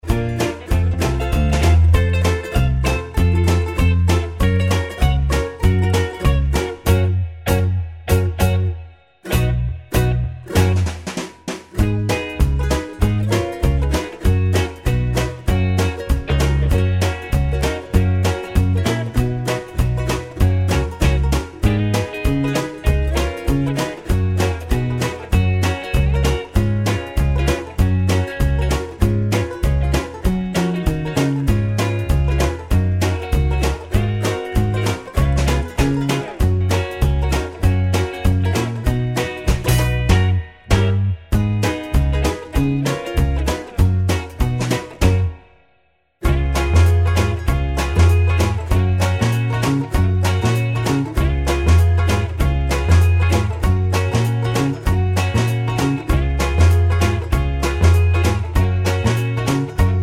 no Backing Vocals Oldies (Female) 2:33 Buy £1.50